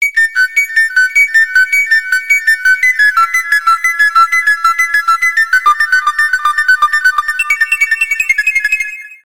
メール通知音やSNS向けの短い音。